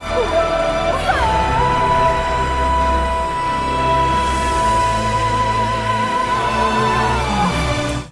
Play, download and share Whooohoooo original sound button!!!!
woohoooo.mp3